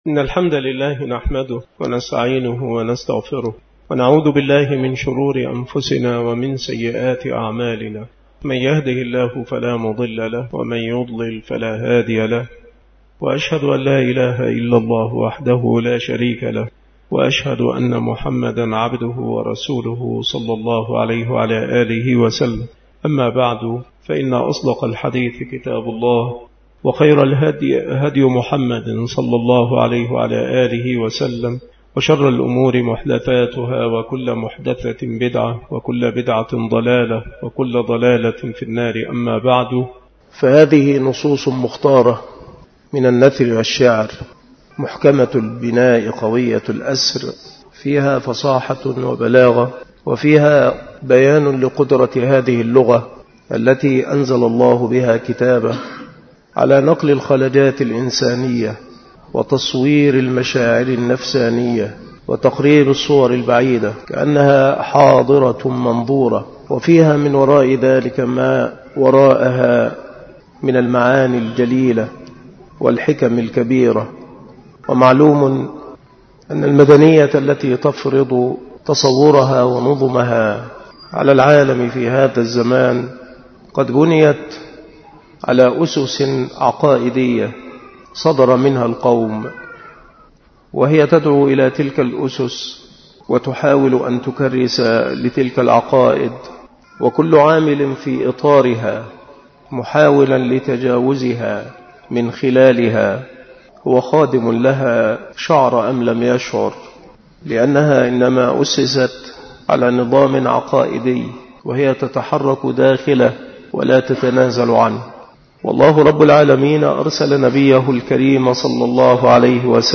مكان إلقاء هذه المحاضرة بمسجد أولاد غانم بمدينة منوف - محافظة المنوفية - مصر